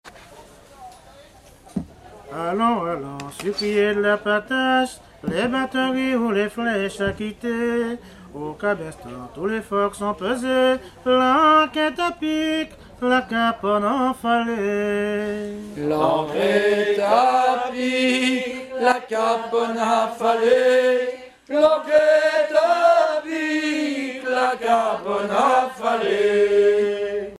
Genre strophique
chansons anciennes recueillies en Guadeloupe
Pièce musicale inédite